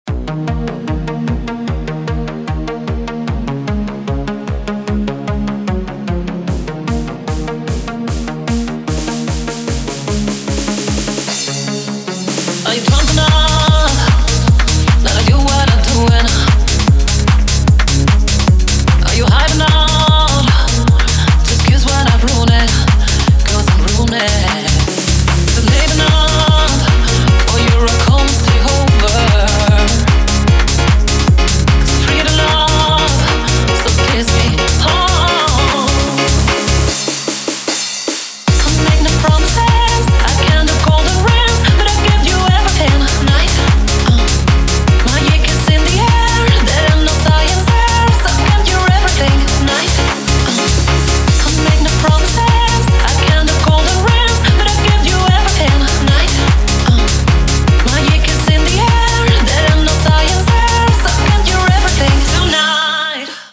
• Качество: 256, Stereo
громкие
женский вокал
Electronic
электронная музыка
клавишные
энергичные
быстрые
Hard dance